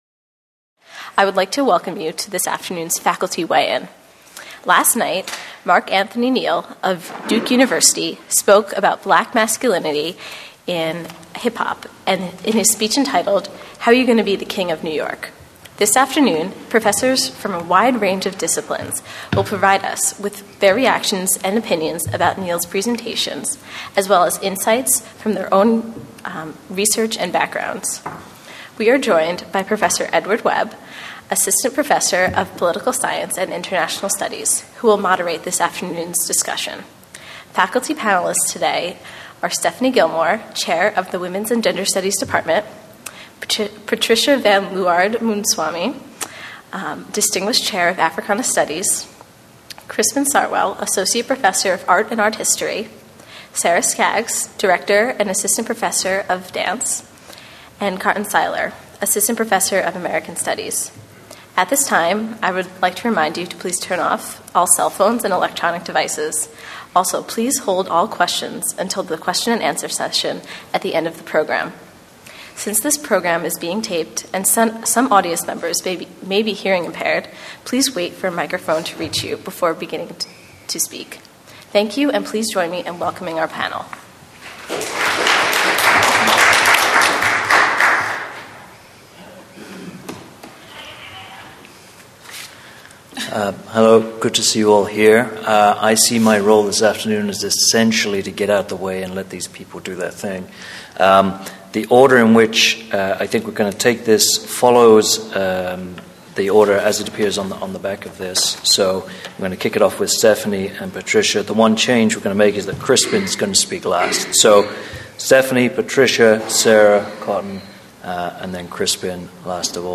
Faculty panel discussion held on 4/9/10.